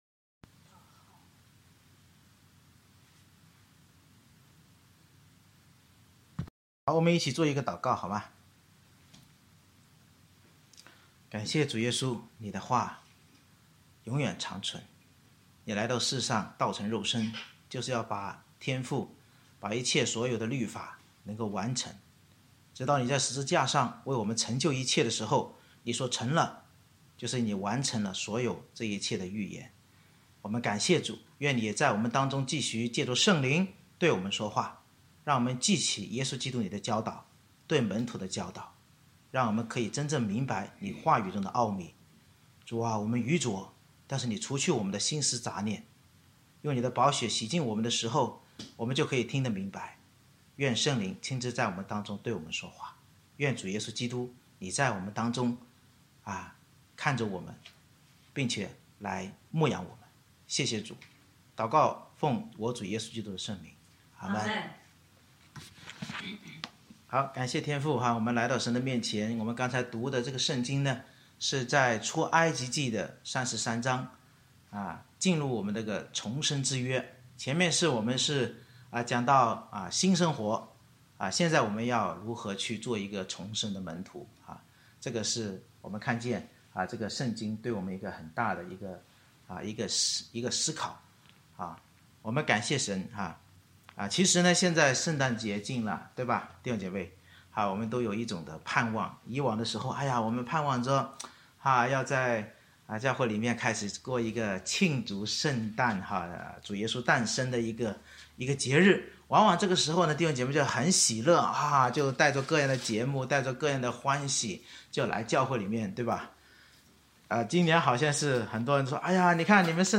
December 13, 2020 作门徒——重生之约（上） Series: 《出埃及记》讲道系列 Passage: 出埃及记33章 Service Type: 主日崇拜 以色列人因背约受审判时悔改离罪并仰望摩西在神磐石上荣耀代求而与神和好，教导我们在犯罪痛苦时若悔改离弃罪彻底顺服并仰望基督十架荣耀必重生作主门徒。